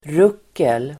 Ladda ner uttalet
Uttal: [r'uk:el]